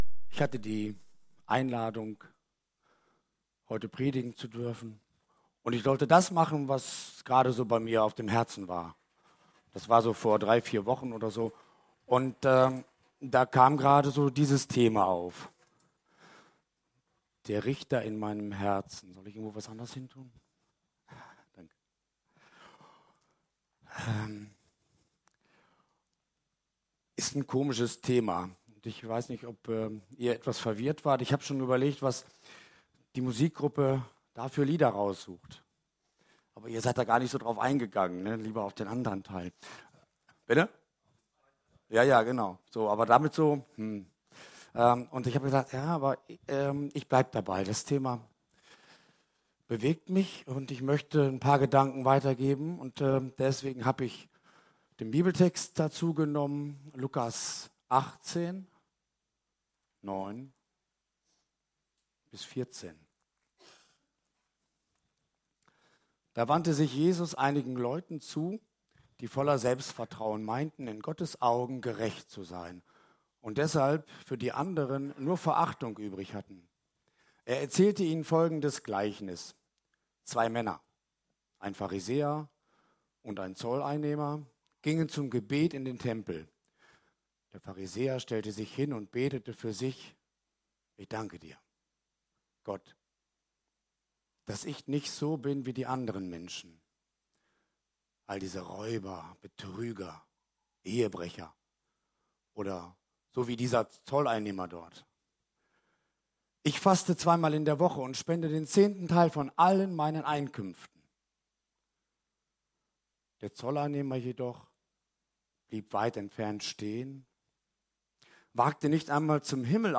Predigt vom 12.